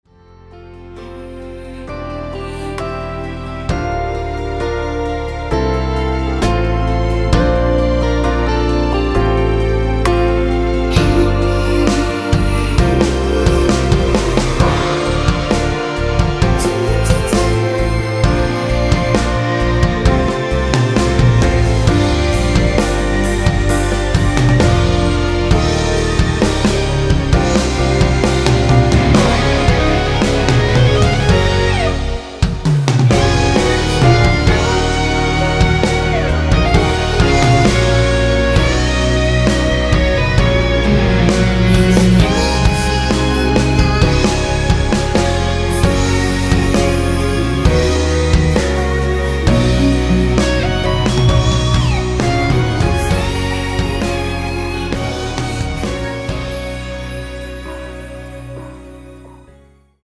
원키에서(+4)올린 (1절앞+후렴)으로 진행되는 코러스 포함된 MR입니다.
앞부분30초, 뒷부분30초씩 편집해서 올려 드리고 있습니다.
중간에 음이 끈어지고 다시 나오는 이유는